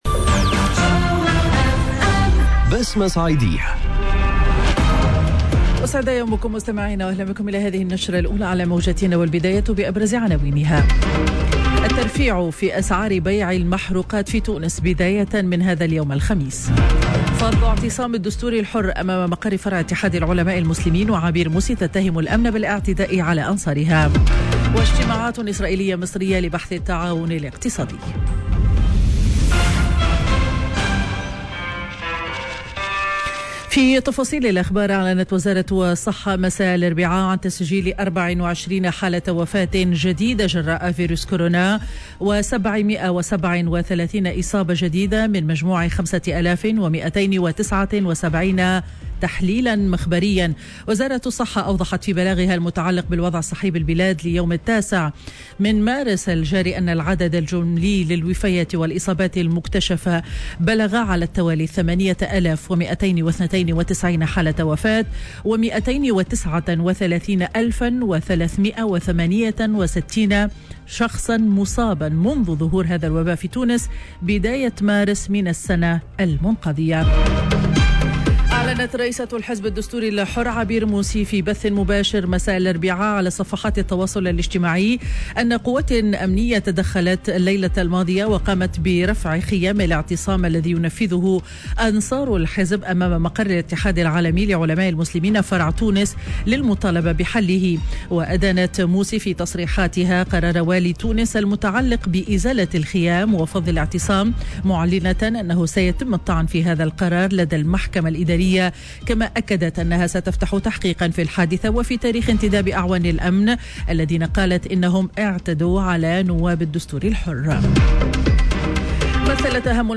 نشرة أخبار السابعة صباحا ليوم الخميس 11 مارس 2021